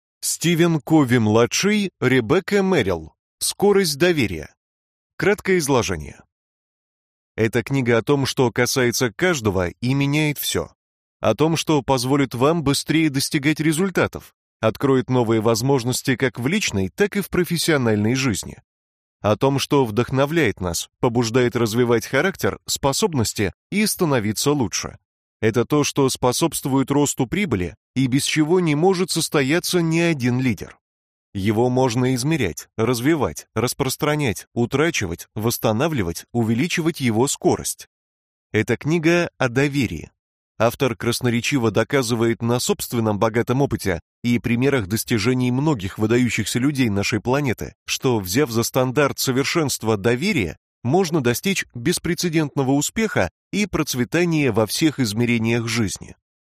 Аудиокнига Скорость доверия (краткое изложение книги) | Библиотека аудиокниг